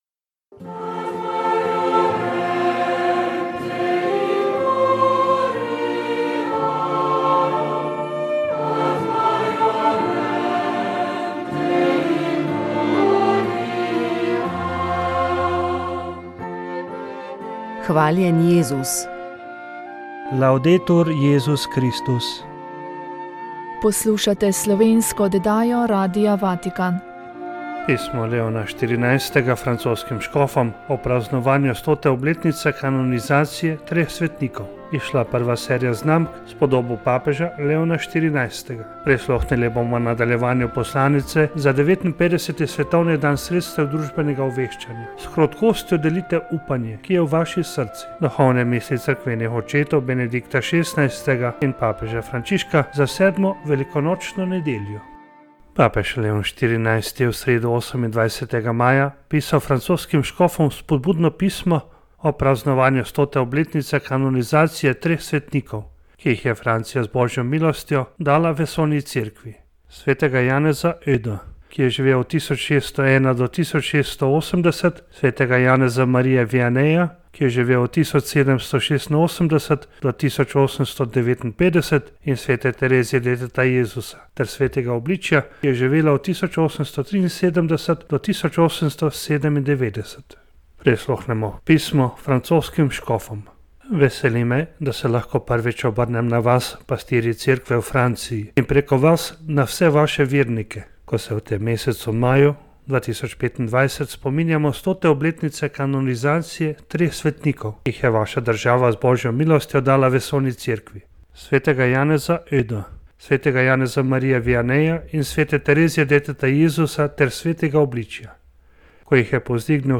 Sv. maša iz bazilike Marije Pomagaj na Brezjah 26. 5.